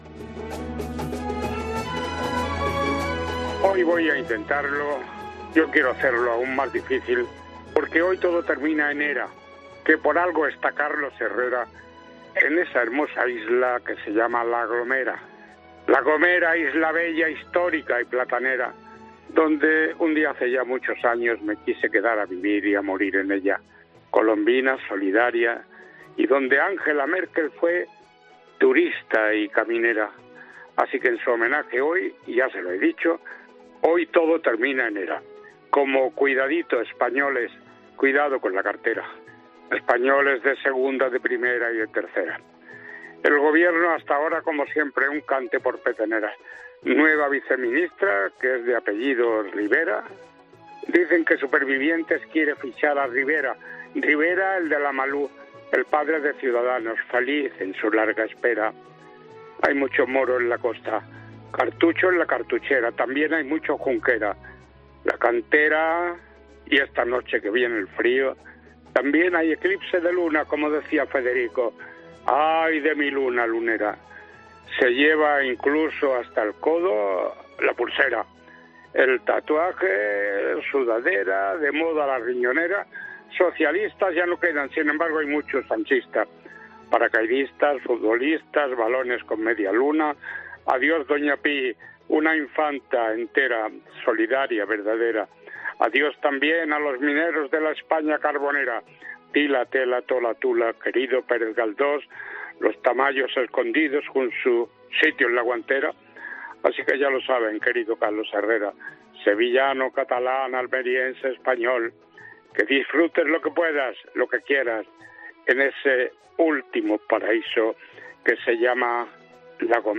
No te pierdas el repaso a la actualidad de la semana que este viernes ha preparado para ti el veterano periodista